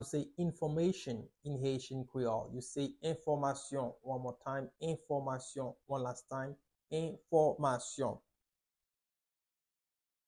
Pronunciation:
11.How-to-say-Information-in-Haitian-Creole-–-Enfomayson-pronunciation.mp3